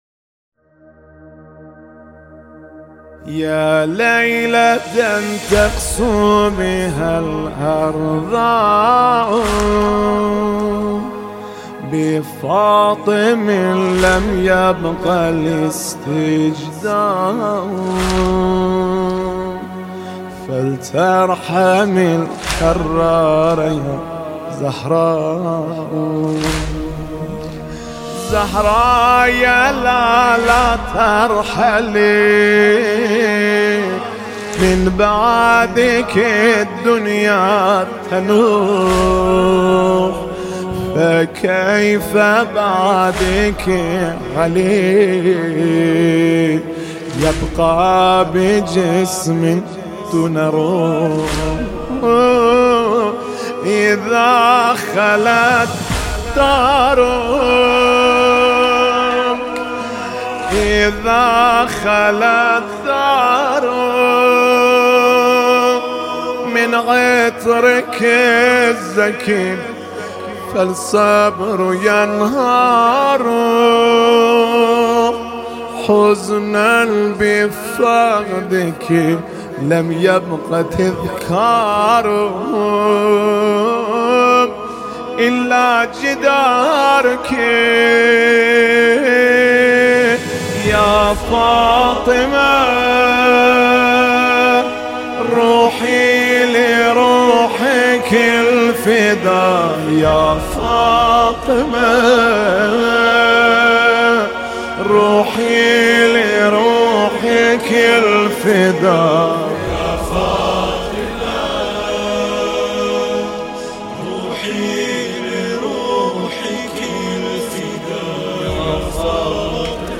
سنگین واحد سینه زنی روضه سوزناک